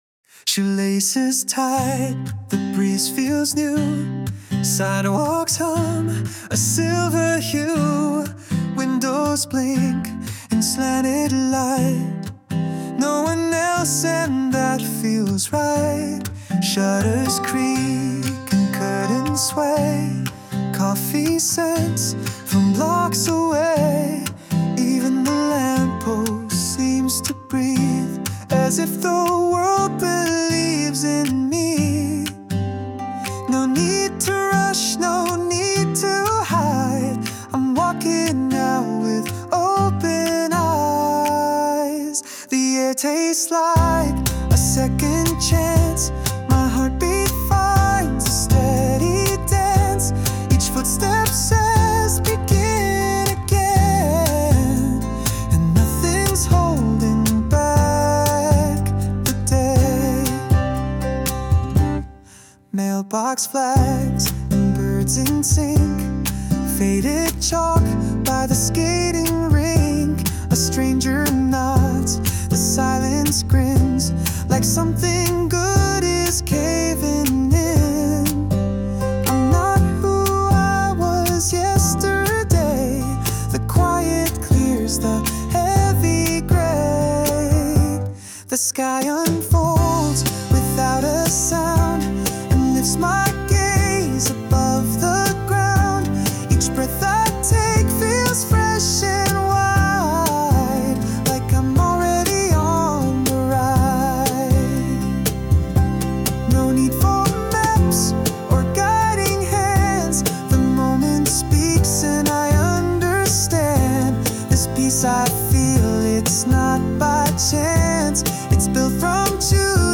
洋楽男性ボーカル
著作権フリーオリジナルBGMです。
男性ボーカル（洋楽・英語）曲です。